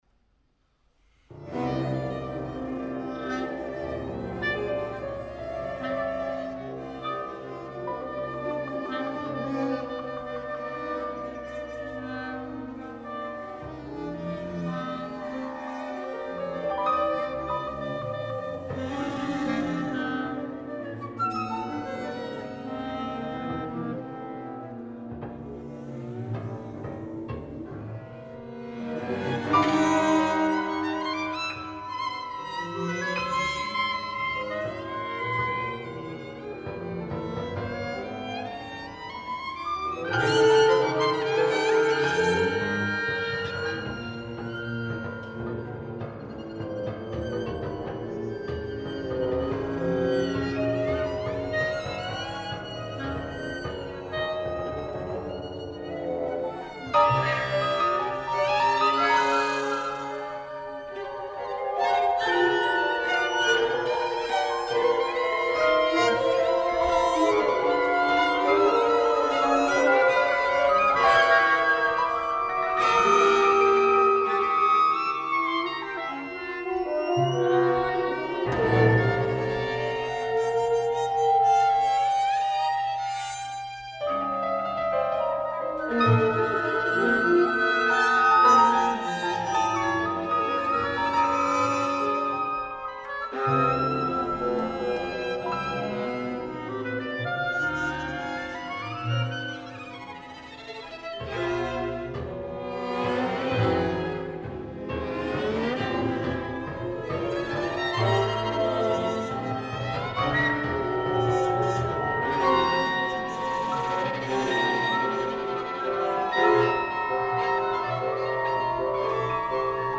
Para ensamble de 14 músicos